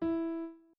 01_院长房间_钢琴_06.wav